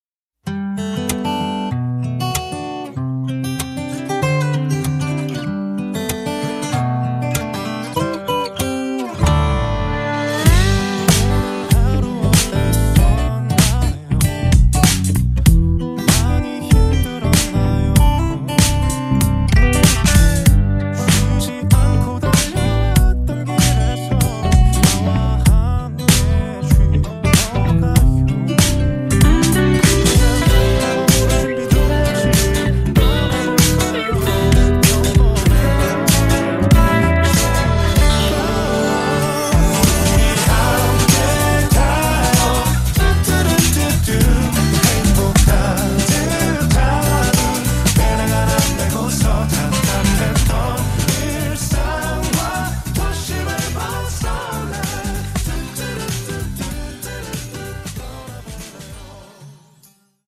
음정 -1키 3:18
장르 가요 구분 Voice MR
보이스 MR은 가이드 보컬이 포함되어 있어 유용합니다.